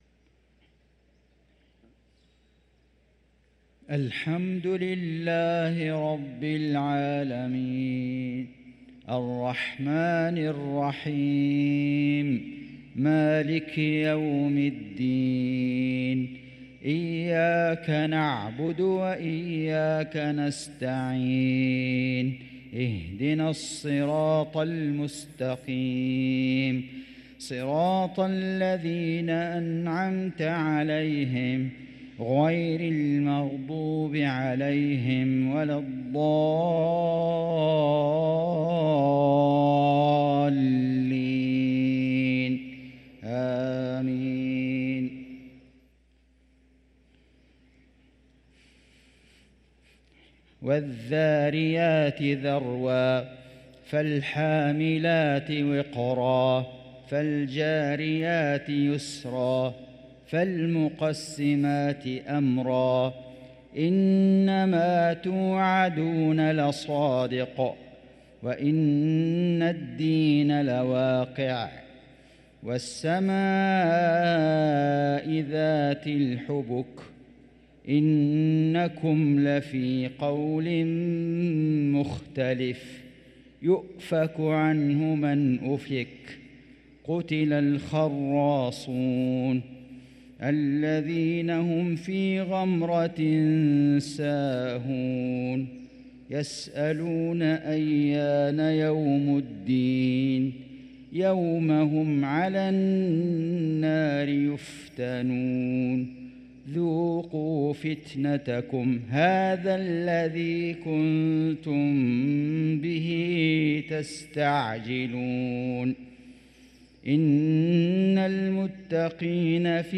صلاة العشاء للقارئ فيصل غزاوي 17 رجب 1445 هـ
تِلَاوَات الْحَرَمَيْن .